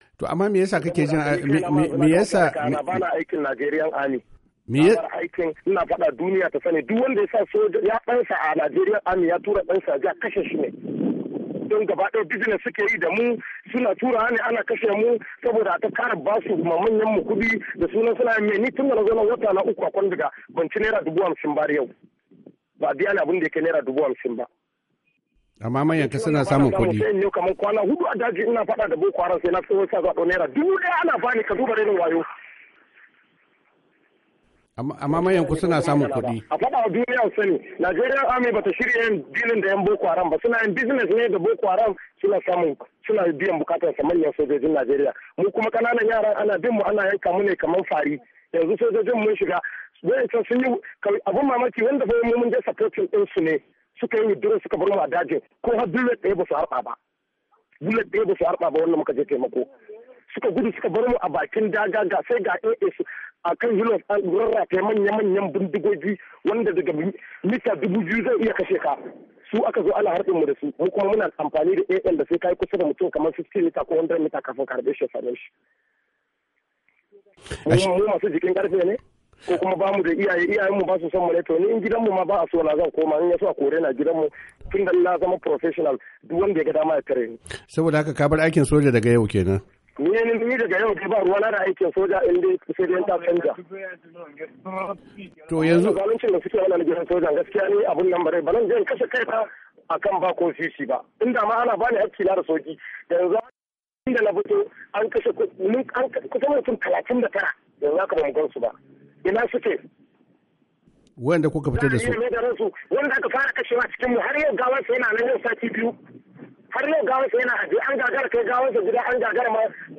Ga kashi na biyu na hirar.